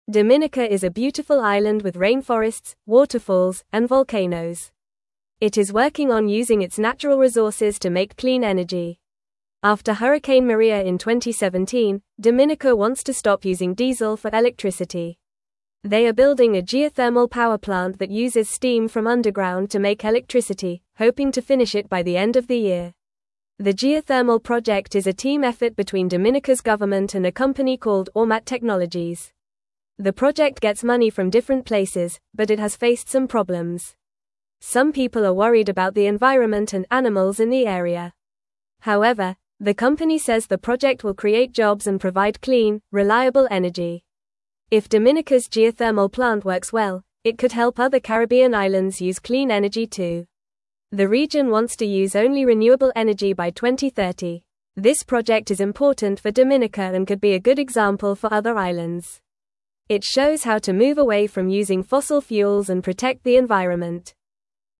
Fast
English-Newsroom-Lower-Intermediate-FAST-Reading-Dominicas-Clean-Energy-Plan-for-a-Bright-Future.mp3